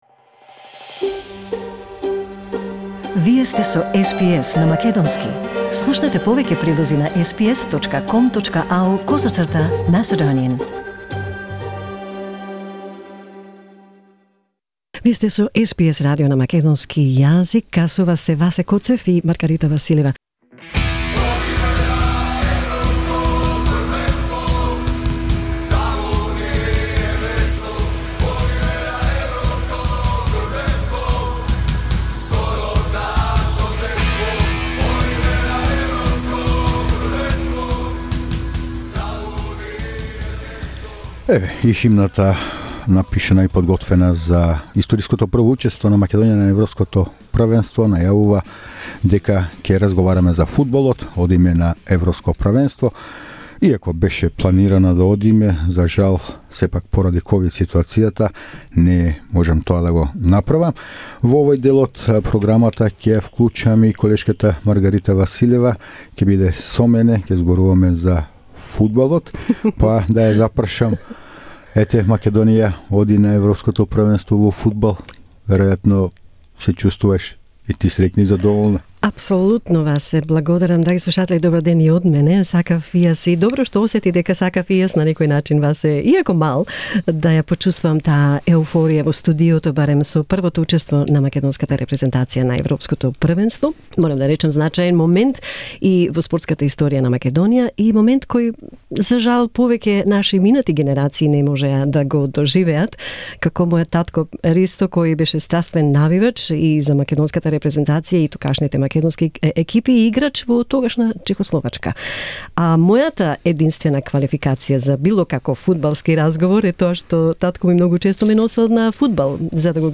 Пред заминувањето за Букурешт, Ангеловски во интервју за македонската програма на СБС радио изјави дека и тој и фудбалерите со нетрпение чекаат на првиот натпревар против Австрија и додаде: " Подготвени сме за нови успеси и подвизи.